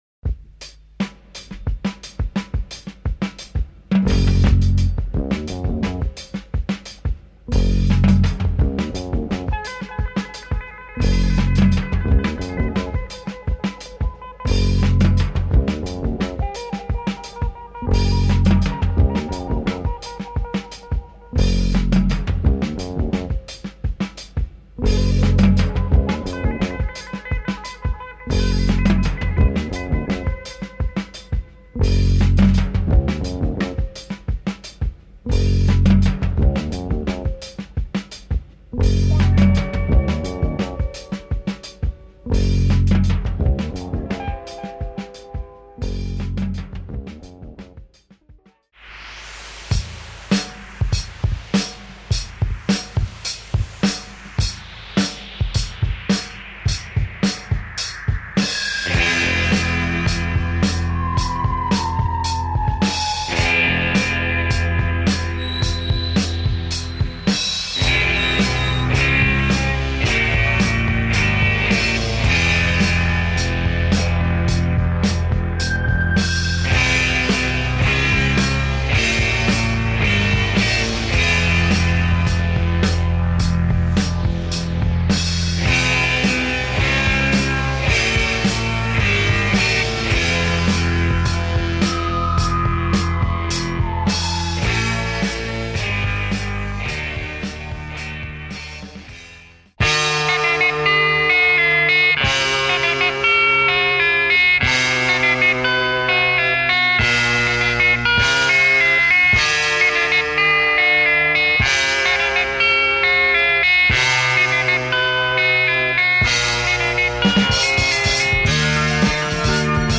Incredible British library
lots of spacy / syncopated / abstract breaks
Heavy sound !